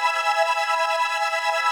SaS_MovingPad05_140-E.wav